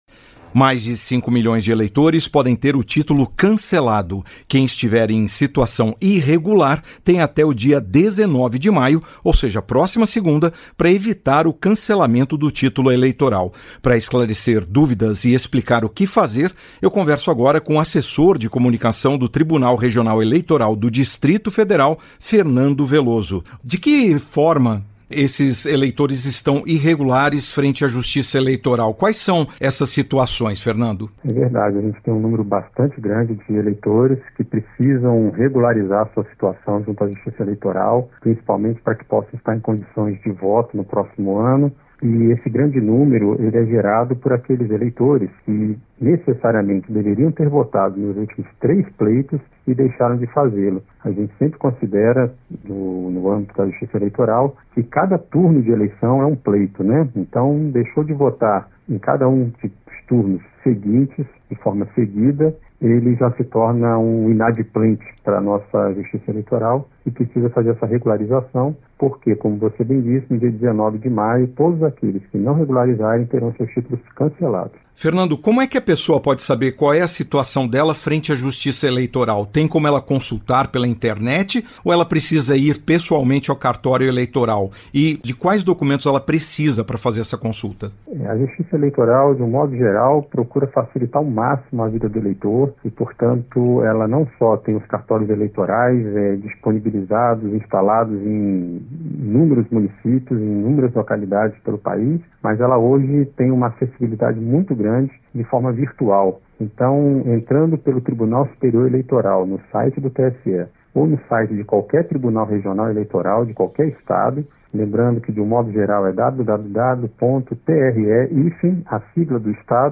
Entrevista: saiba como regularizar pendências com a justiça eleitoral